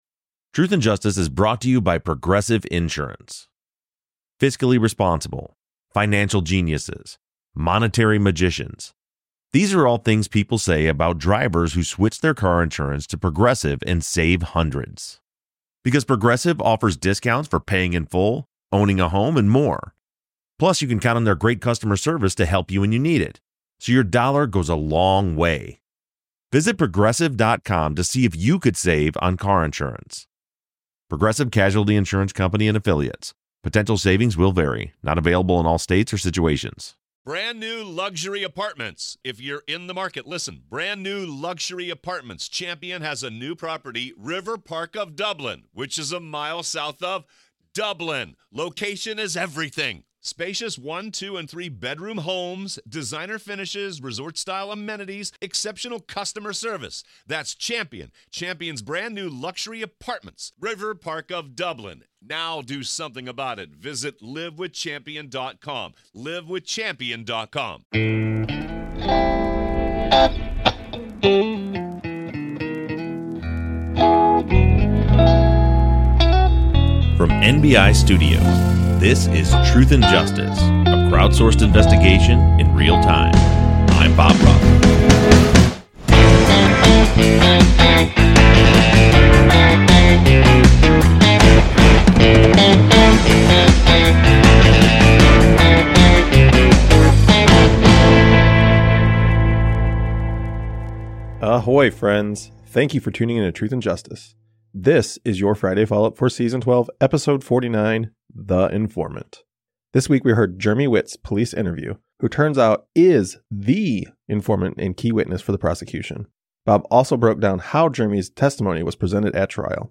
The team takes a lot of great listener questions as they break down all the details and put them back together again.